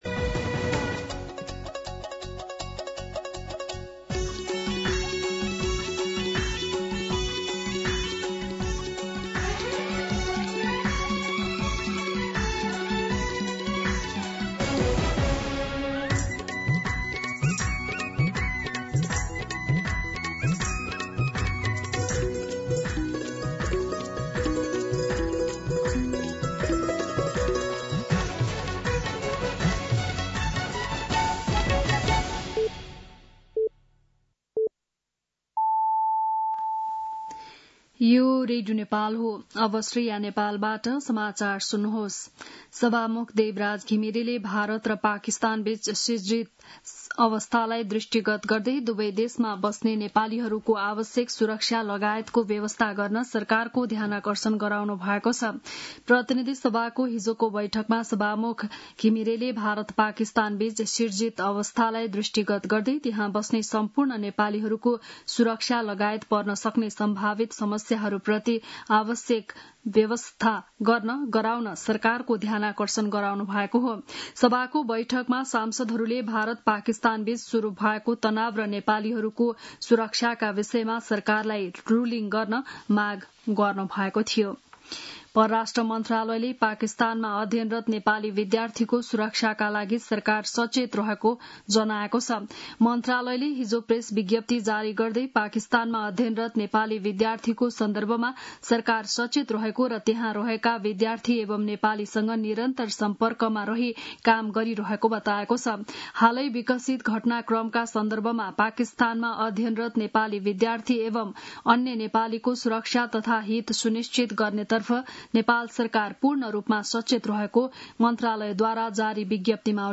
बिहान ११ बजेको नेपाली समाचार : २७ वैशाख , २०८२
11-am-Nepali-News-2.mp3